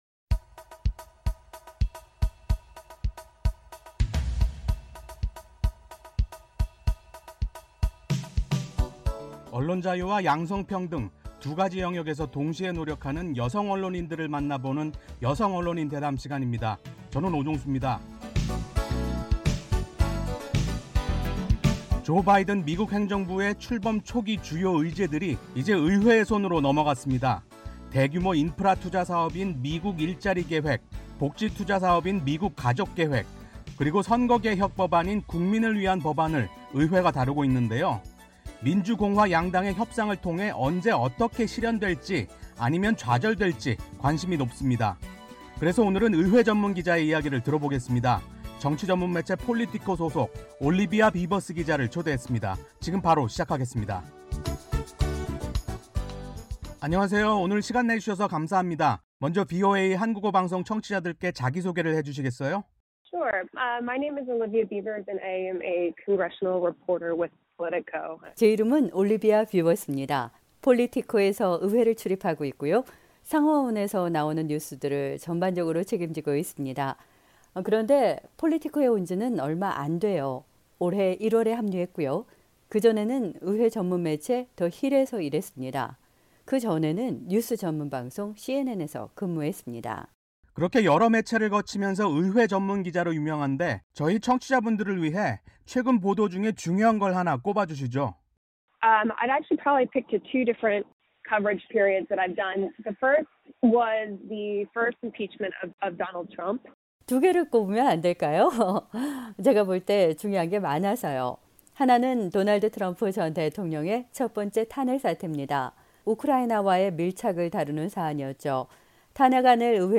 [여성 언론인 대담] "대통령과 말다툼